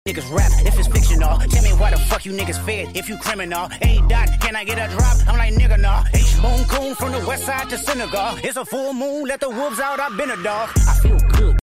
TDE XMAS 11th ANNUAL TOY DRIVE AND CONCERT